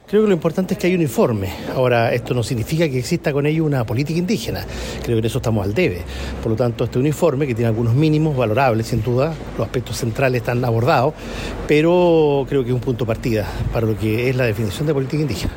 Al respecto, distintos actores del mundo político, regional y nacional, entregaron sus puntos de vista tras la entrega del citado informe, destacando el senador y presidente del Partido Por la Democracia, PPD, Jaime Quintana, quien resaltó que al menos hay una base para avanzar a una política indígena.